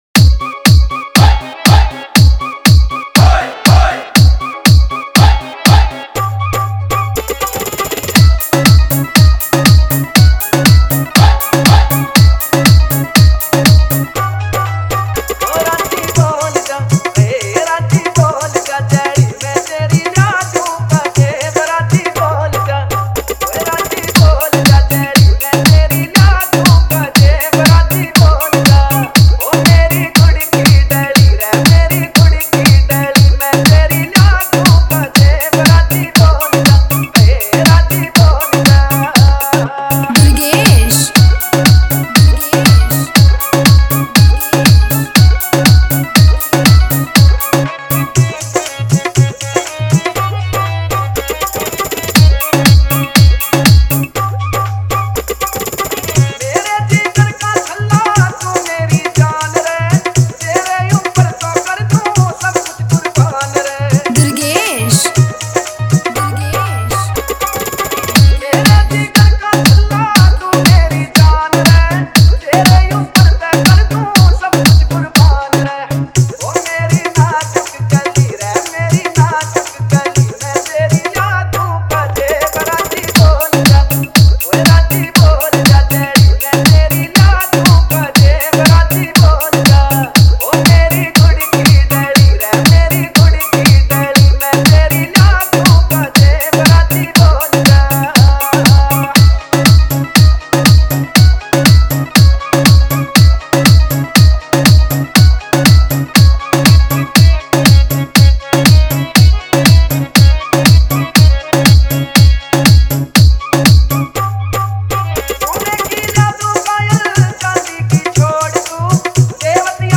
Bollywood Dj Remix Songs